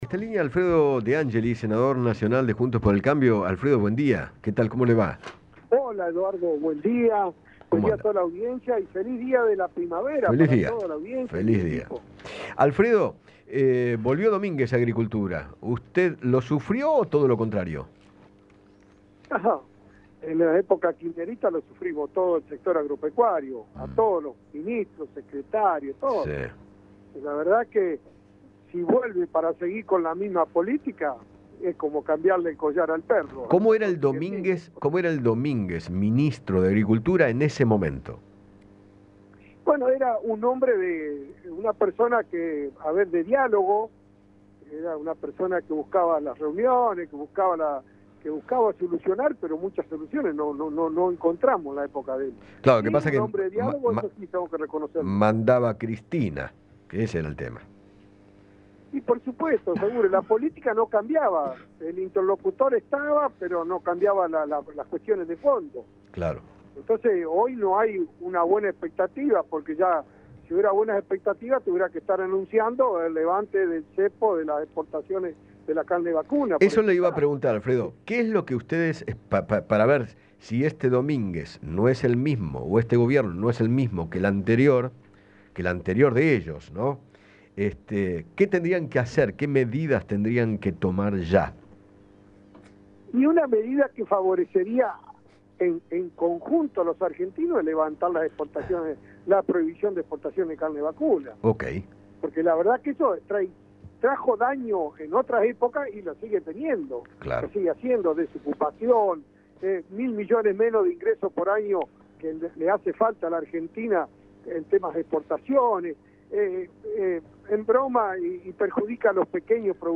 Alfredo De Angeli, senador nacional de Juntos por el Cambio, conversó con Eduardo Feinmann sobre la designación de Julián Domínguez como nuevo ministro de Agricultura, Ganadería y Pesca y sostuvo que “era un hombre de diálogo, pero no solucionaba nada”.